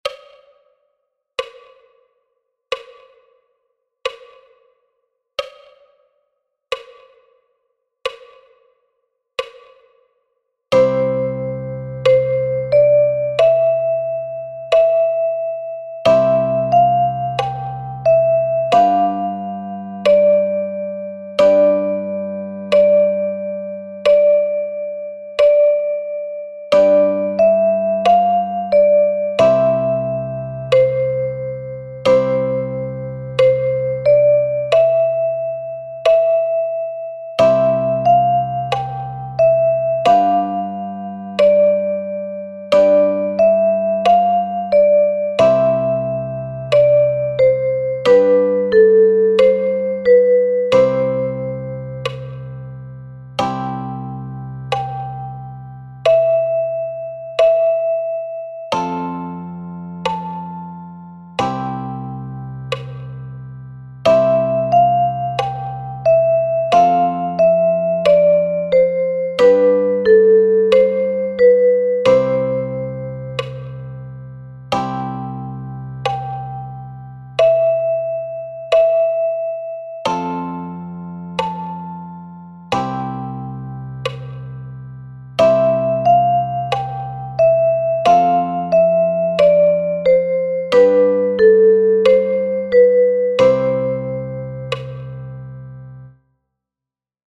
Der Song ist in einem langsamen Tempo aufgenommen. Die Instrumentierung wurde so gewählt dass sich der Klang möglichst wenig mit dem des eigenen Instrumentes vermischt.